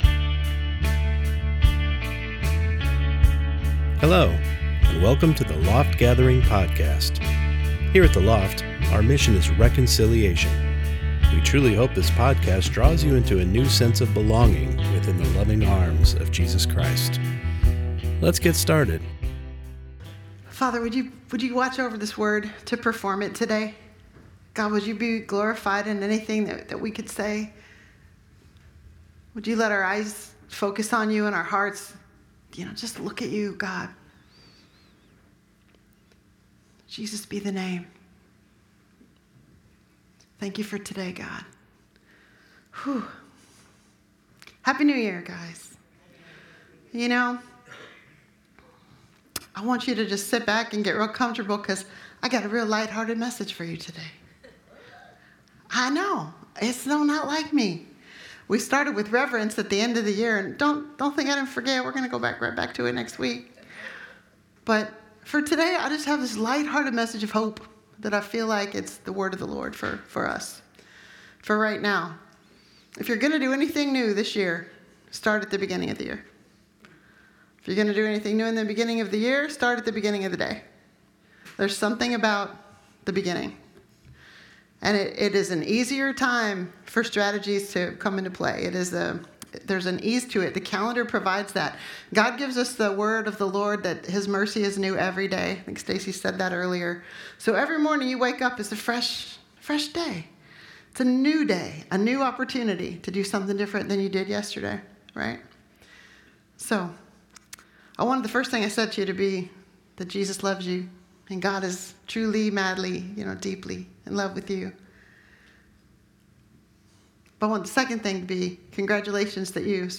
Service: Sunday Morning Service